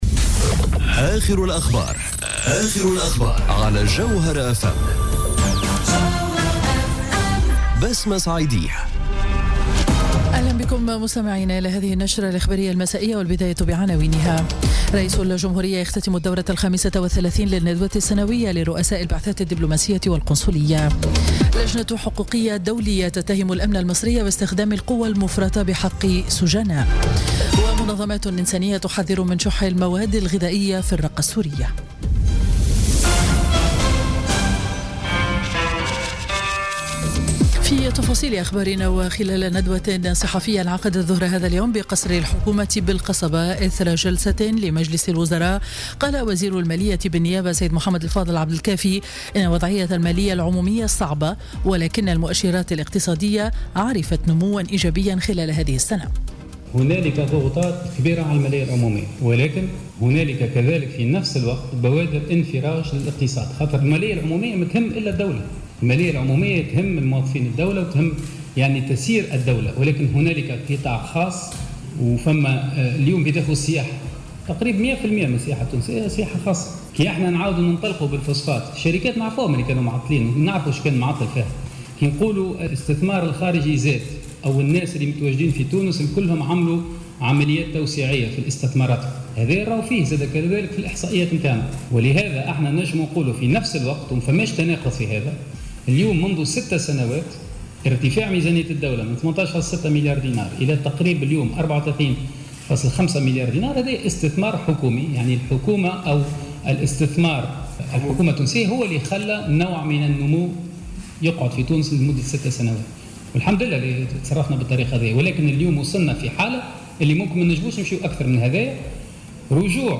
نشرة أخبار السابعة مساء ليوم الاثنين 31 جويلية 2017